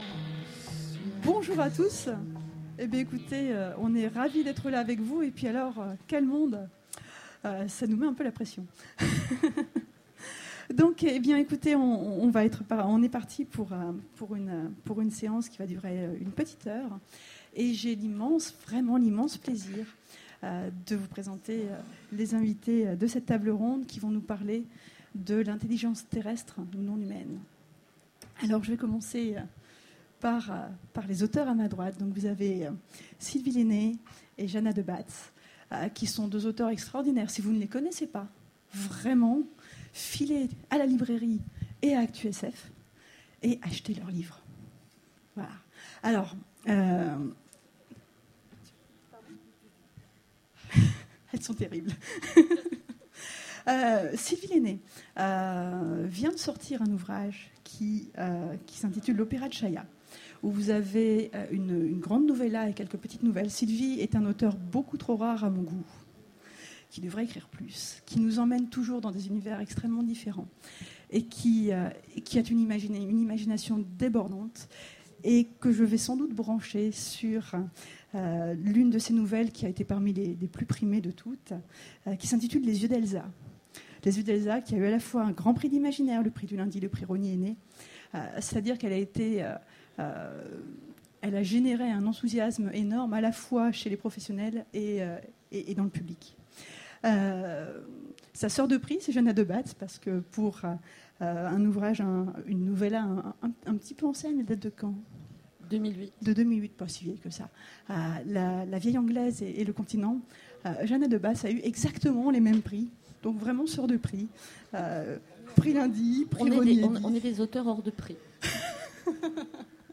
Mots-clés Intelligence Conférence Partager cet article